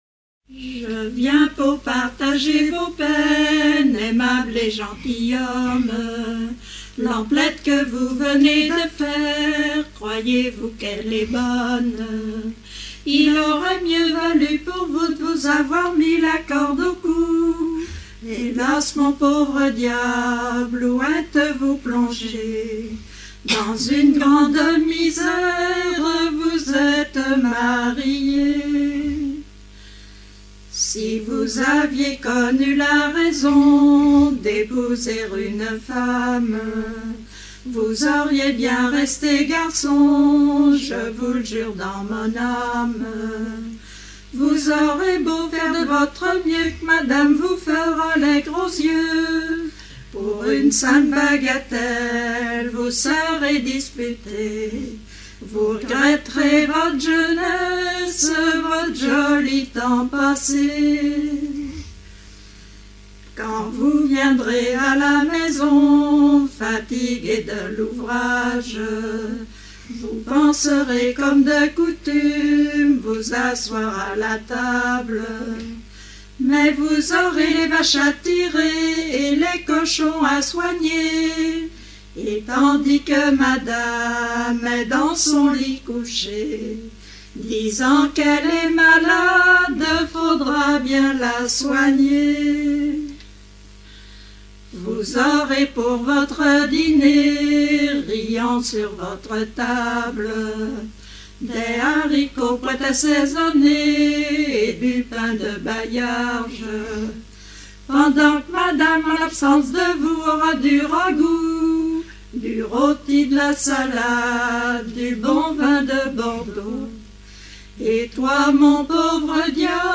circonstance : fiançaille, noce ;
Pièce musicale éditée